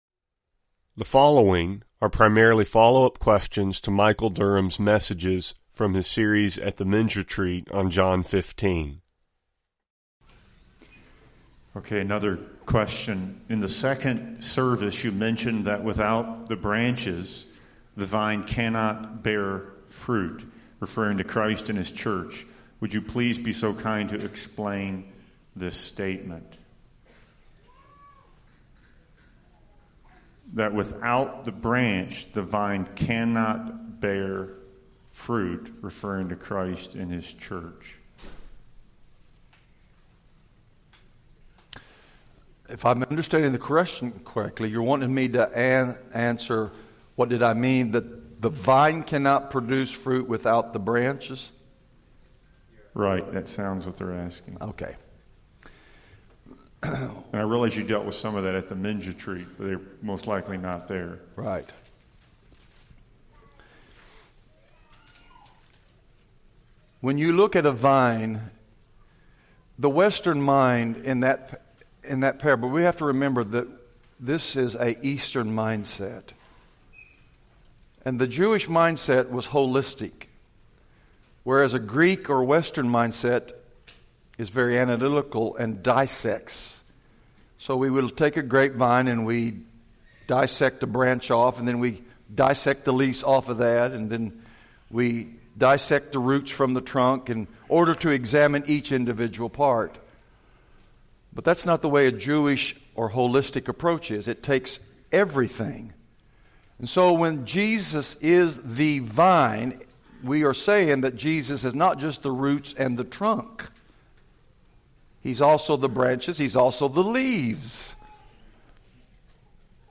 Question and Answer Session on Abiding in Christ